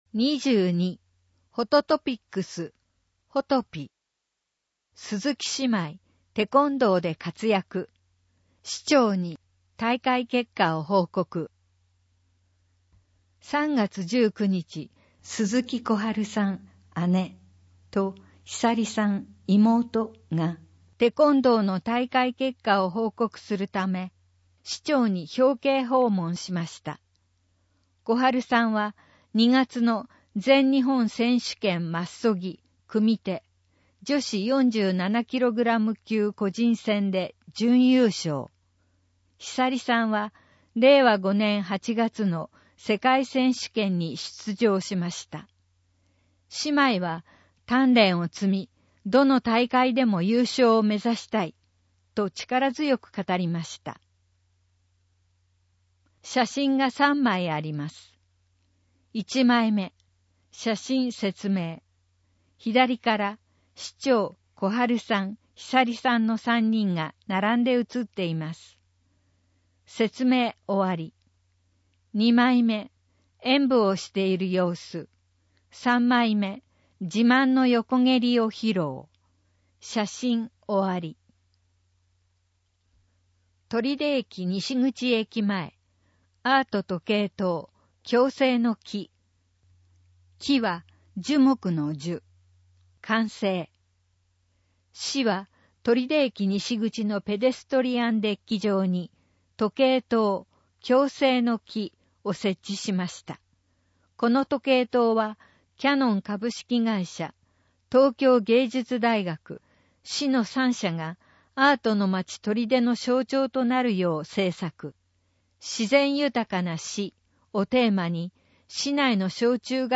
取手市の市報「広報とりで」2024年5月1日号の内容を音声で聞くことができます。音声データは市内のボランティア団体、取手朗読奉仕会「ぶんぶん」の皆さんのご協力により作成しています。